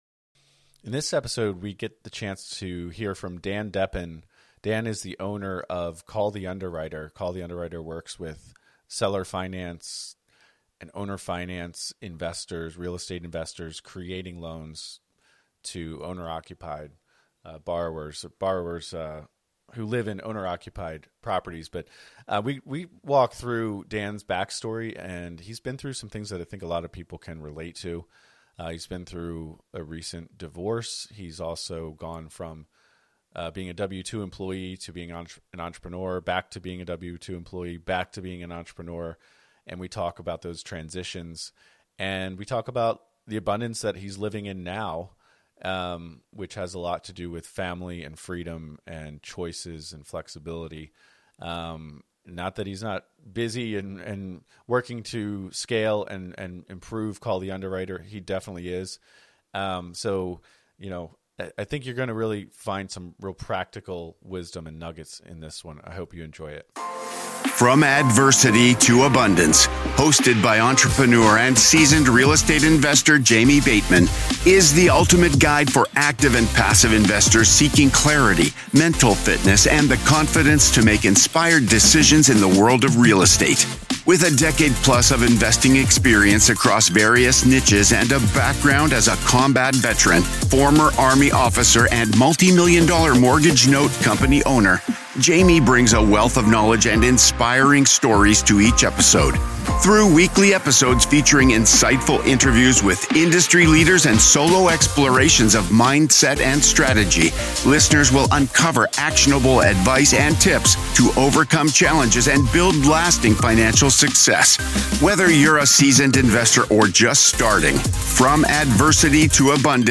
Each week, join us as we dive into the compelling world of real estate through the lens of mental fitness, where challenges transform into opportunities. Our show brings you riveting, interview-based stories from seasoned professionals and inspiring newcomers alike, each sharing their unique journey from struggle to success in the competitive arena of real estate.